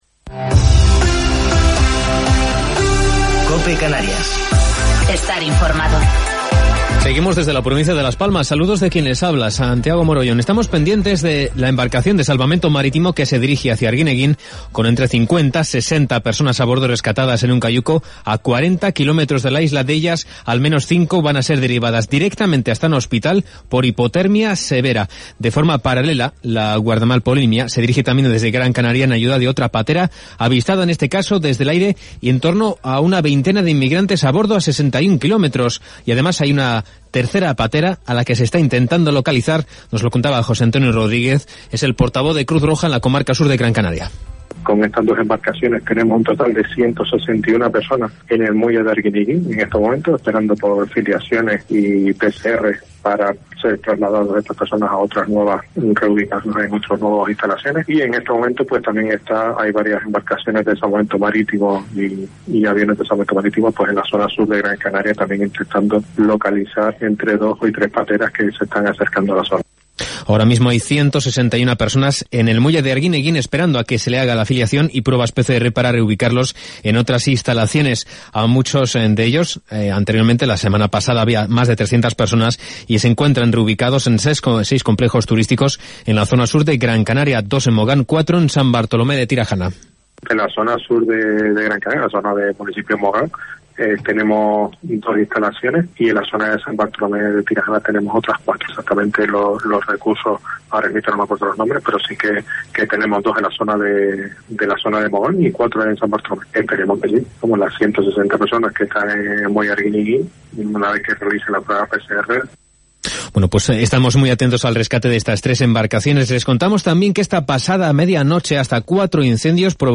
Informativo local 2 de Octubre del 2020